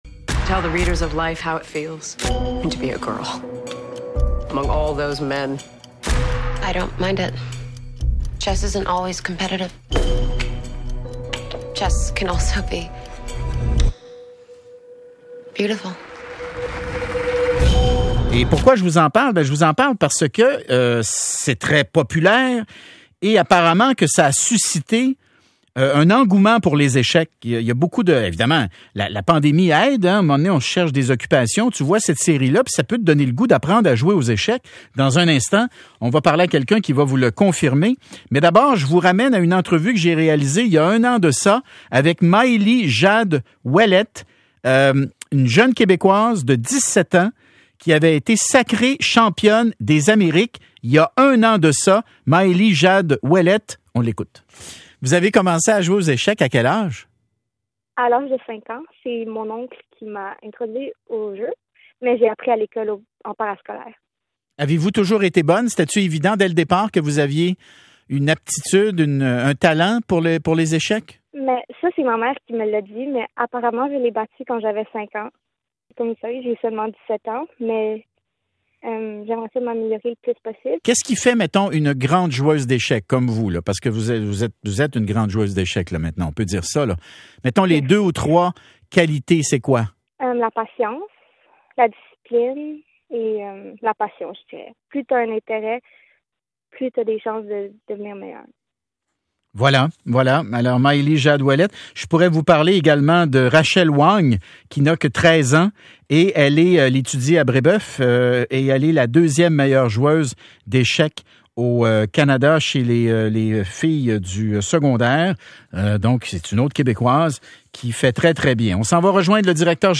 Bel entrevue